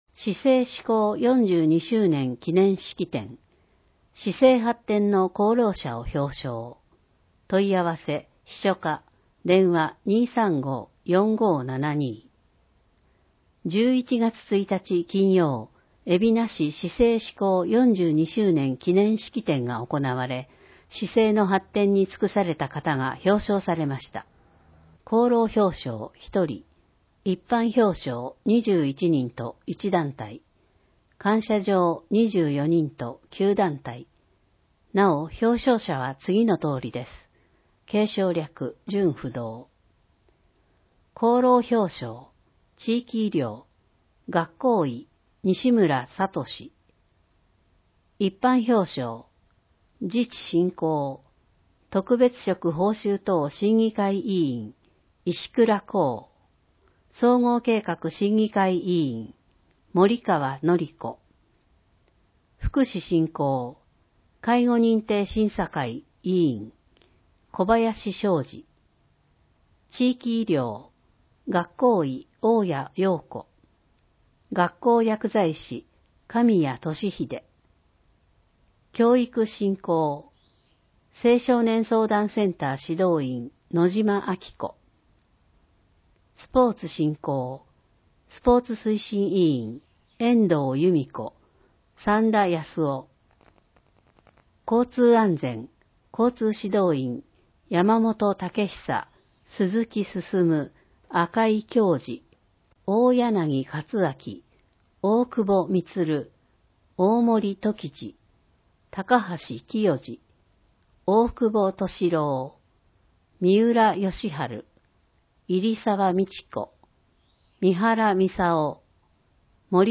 ※音声版は、音声訳ボランティア「矢ぐるまの会」の協力により、同会が視覚障がい者の方のために作成したものを登載しています。